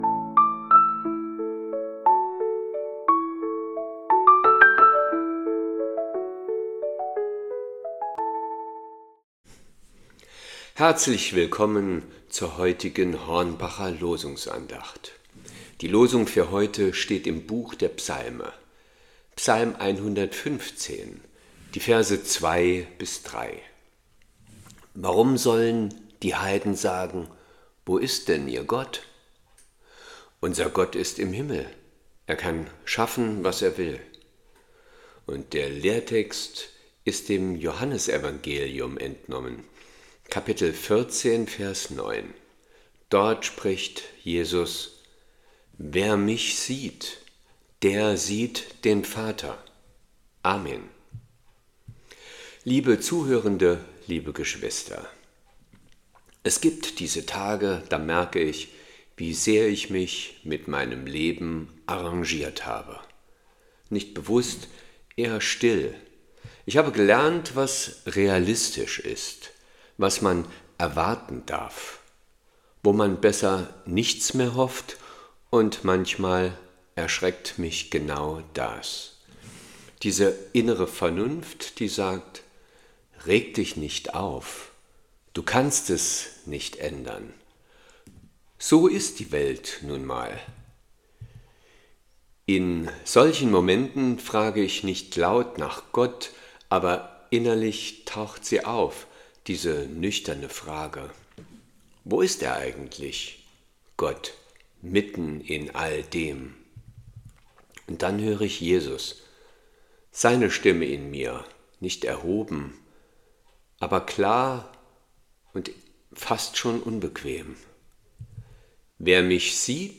Losungsandacht für Samstag, 07.03.2026
Losungsandachten